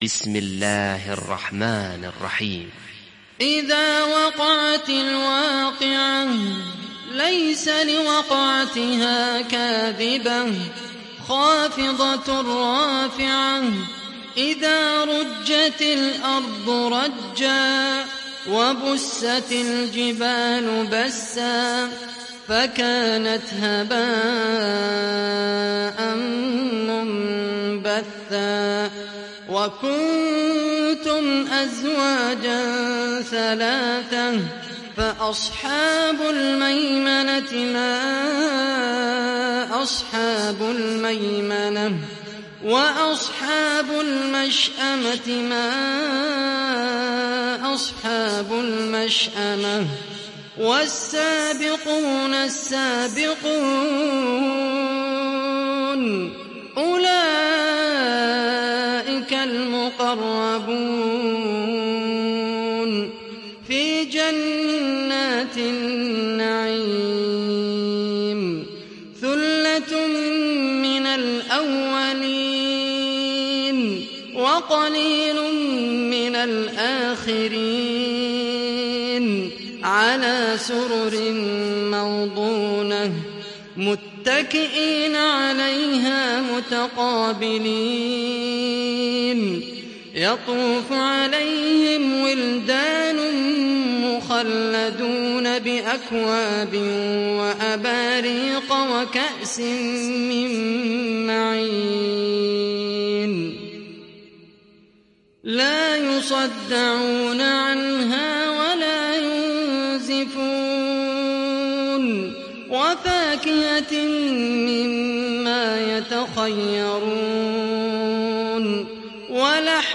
সূরা আল-ওয়াক্বি‘আহ ডাউনলোড mp3 Khaled Al Qahtani উপন্যাস Hafs থেকে Asim, ডাউনলোড করুন এবং কুরআন শুনুন mp3 সম্পূর্ণ সরাসরি লিঙ্ক